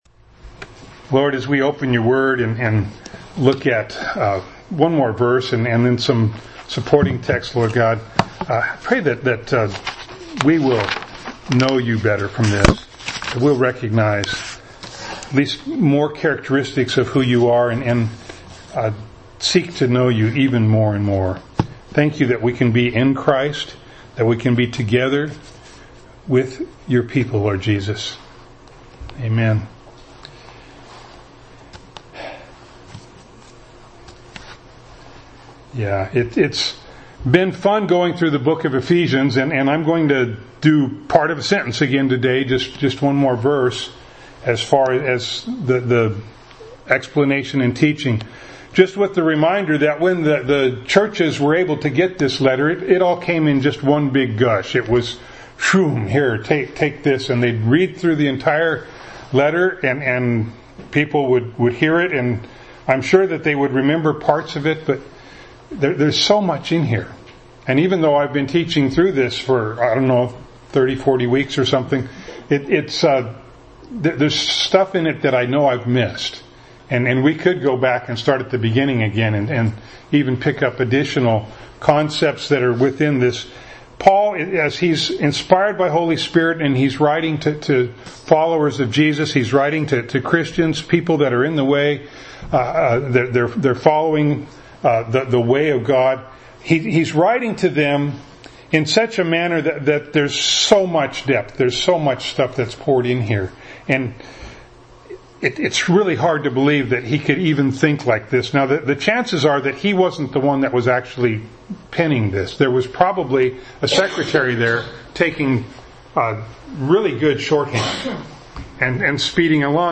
This sermon ends a little abruptly because the recorder batteries went dead at the end.
Service Type: Sunday Morning